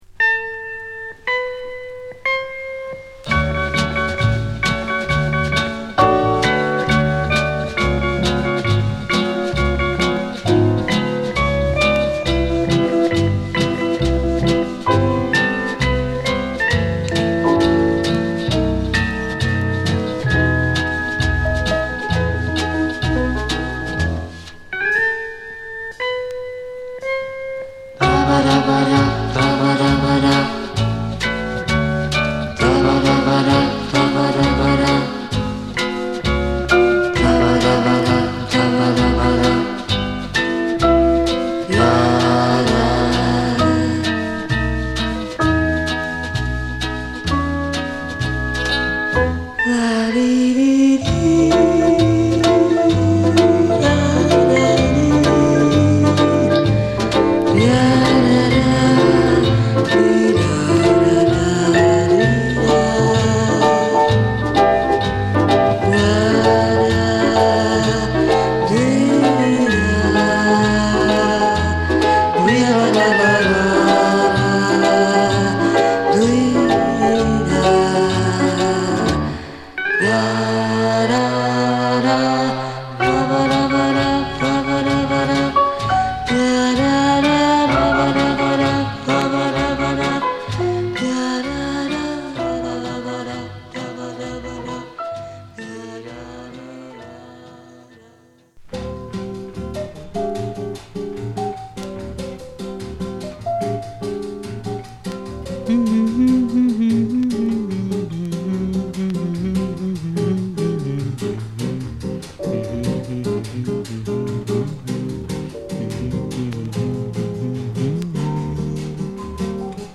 (Orch.)
(Vocal)